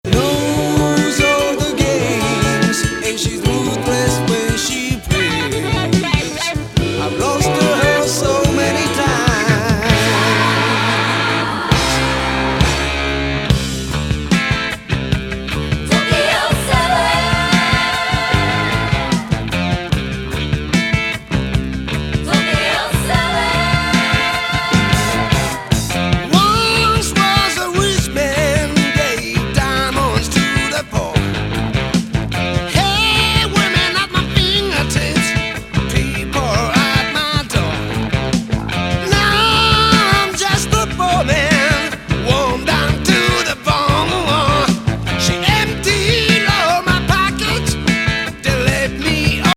ファンキー・ロック多!